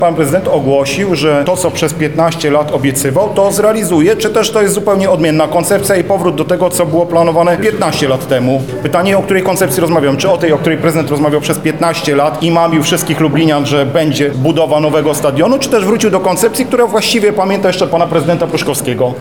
W ratuszu pojawił się także Przewodniczący Klubu Radnych PiS, Robert Derewenda, który odniósł się do aktualnego planu związanego z przebudową stadionu: